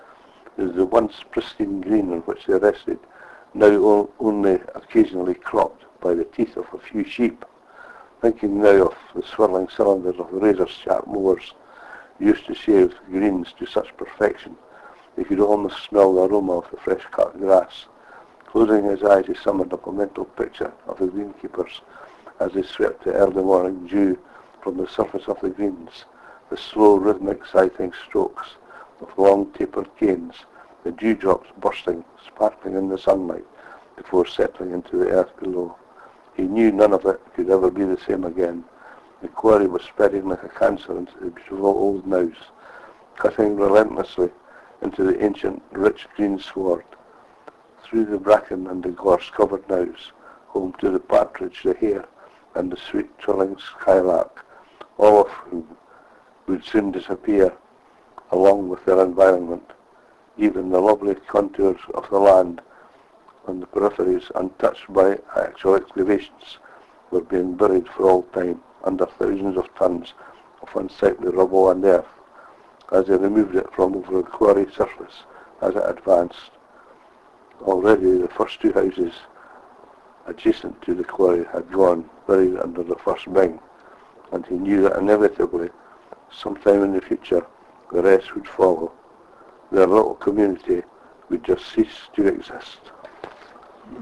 Here he reads an extract from one of his own books, reminiscing on the old golf course when he was a boy.